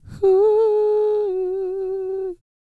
E-CROON P333.wav